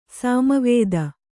♪ sāma vēda